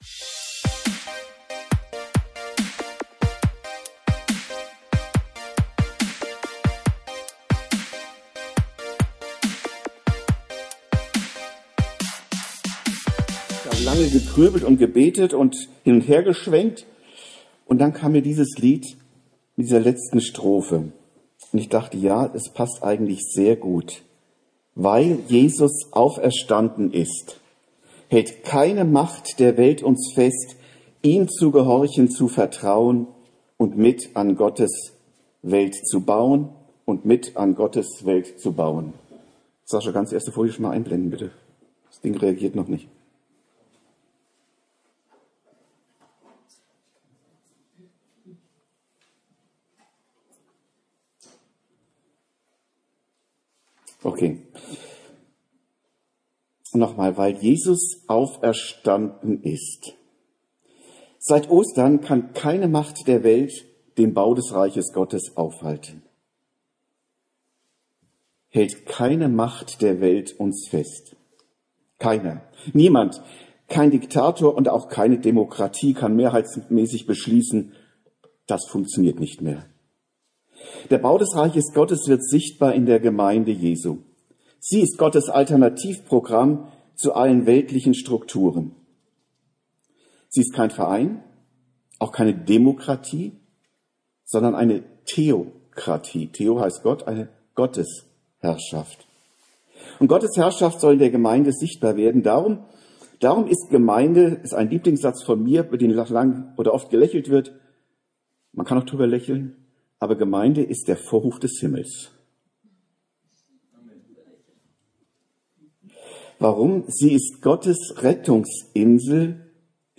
Osterpredigt